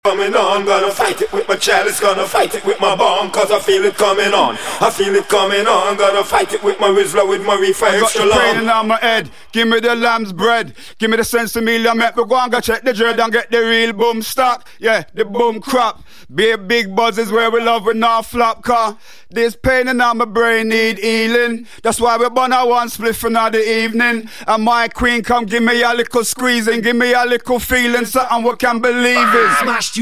Accapella